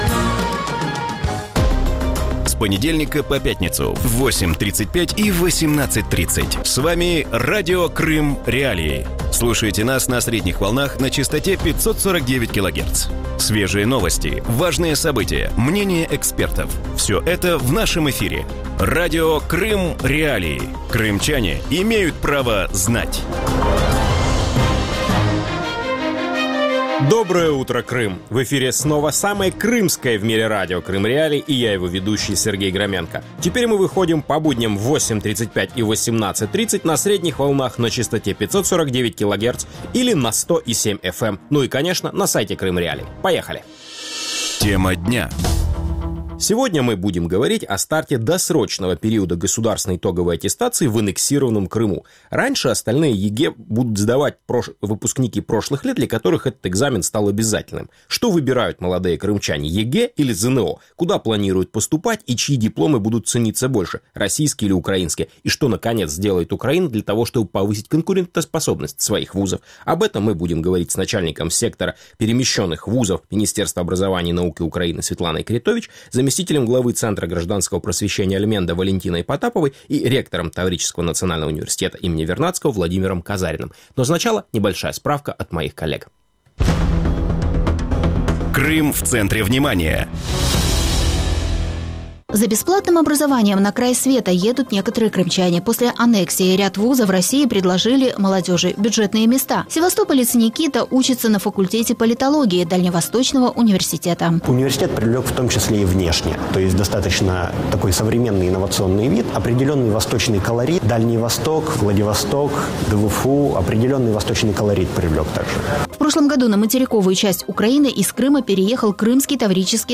Утром в эфире Радио Крым.Реалии говорят о старте досрочного периода государственной итоговой аттестации в аннексированном Крыму.